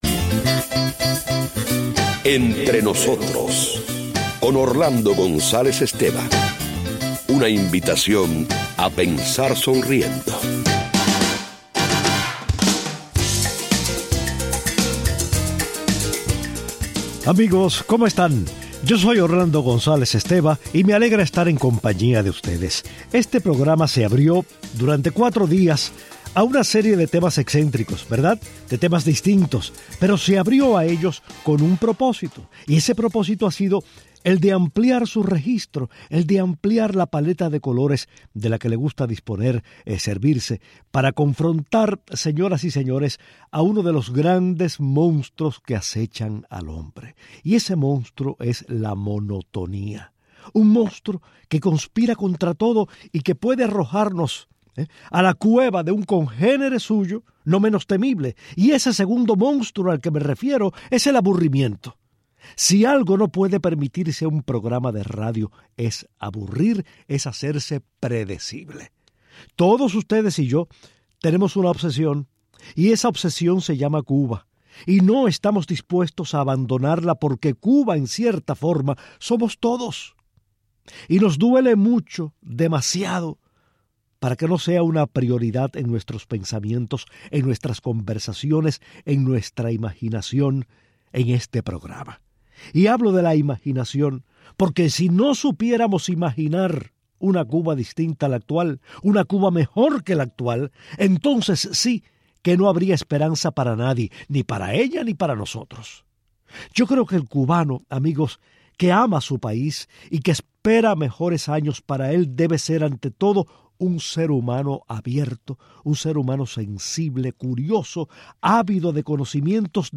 Orlando González Esteva lee y comenta una frase de Martí, algunos correos cuyos autores describen la realidad cubana y se detiene en una carta dirigida, desde la isla, a los Reyes Magos.